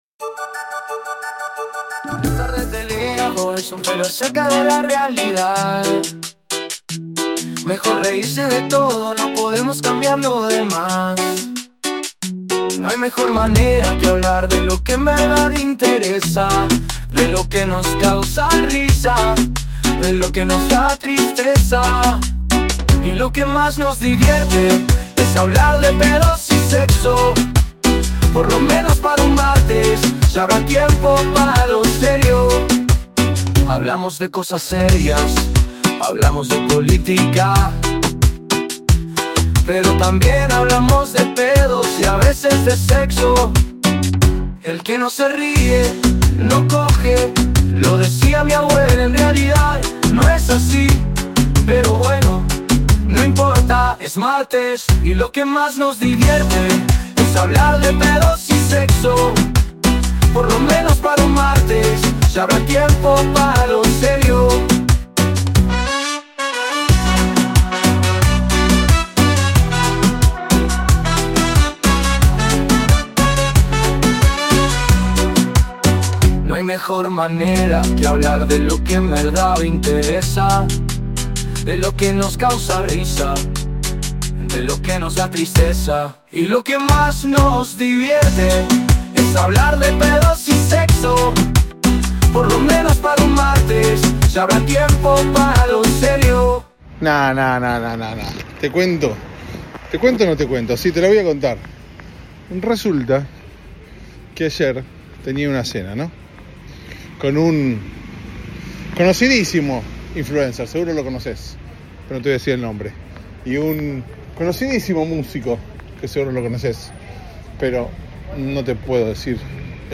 Un martes, una ciudad, un hombre solo grabando un podcast sin temor a que lo roben.